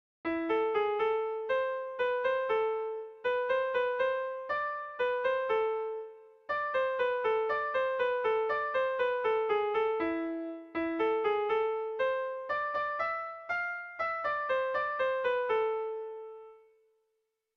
Air de bertsos - Voir fiche   Pour savoir plus sur cette section
ABDA2B2